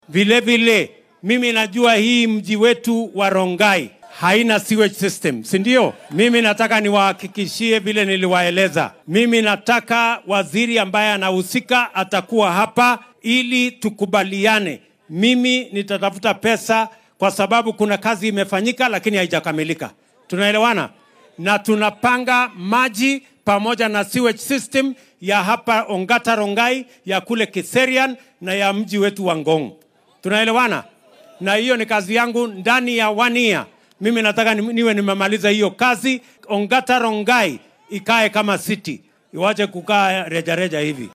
Waxaa uu dadweynaha kula hadlay degmada Ongata Rongai ee dowlad deegaankaasi.